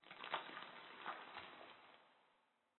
Minecraft Version Minecraft Version latest Latest Release | Latest Snapshot latest / assets / minecraft / sounds / ambient / underwater / additions / earth_crack.ogg Compare With Compare With Latest Release | Latest Snapshot
earth_crack.ogg